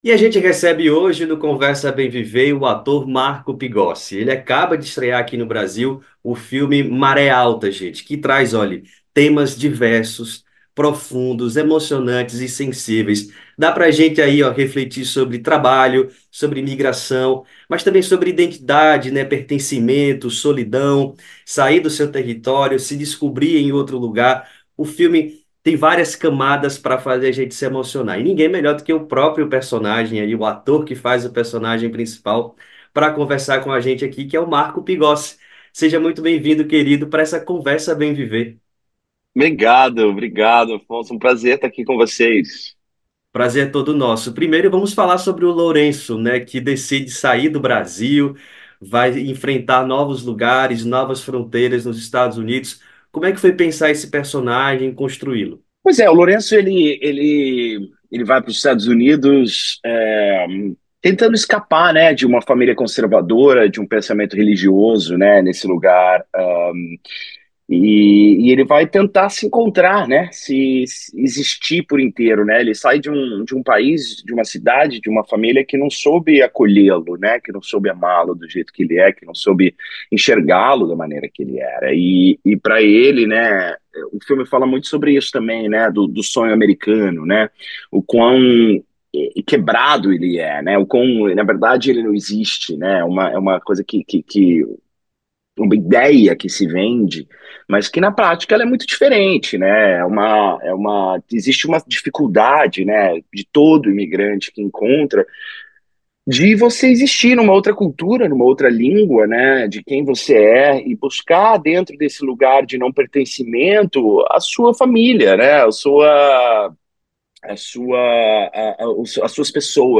Ator foi o entrevistado do podcast Conversa Bem Viver, do Brasil de Fato, desta quarta-feira (2)